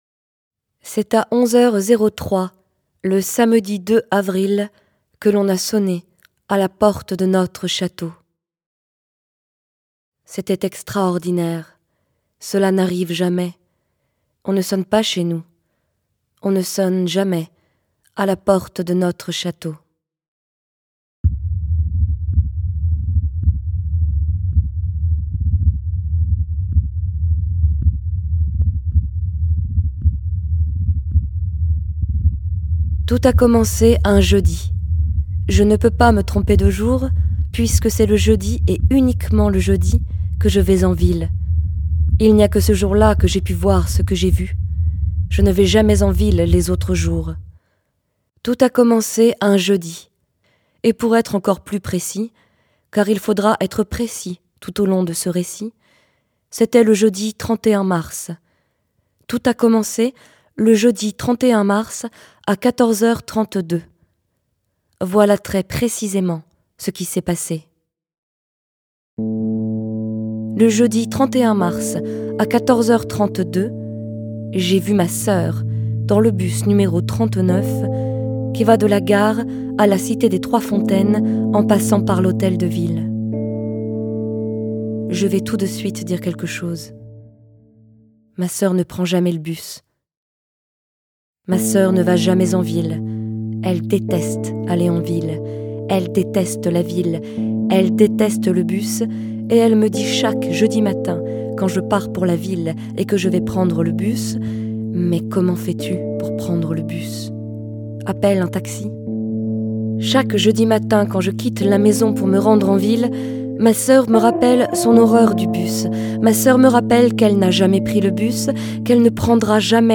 Lecture Musicale « Notre château » d’Emmanuel Regniez
Contrebasse, MS10 Korg Synthétiseur
Rhodes, Juno60 Roland Synthétiseur